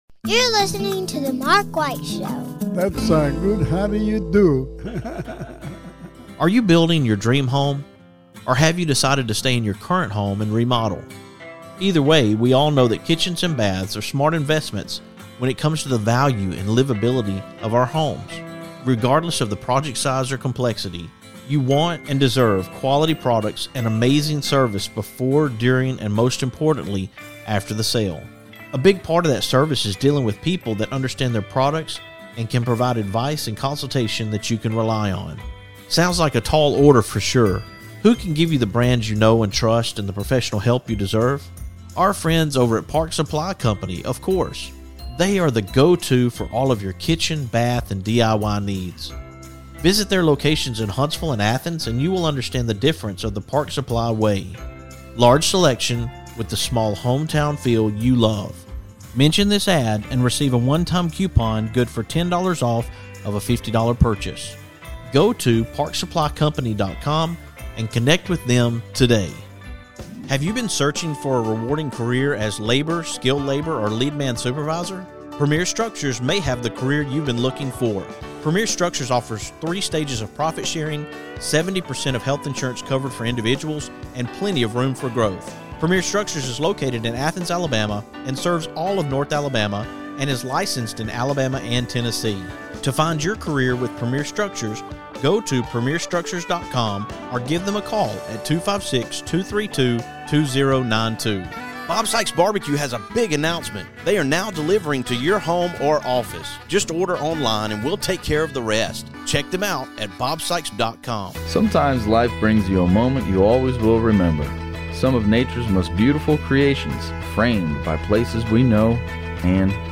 Since we are coming to you from the new studio, I decided to do something a little different for the show today.